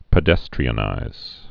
(pĭ-dĕstrē-ə-nīz)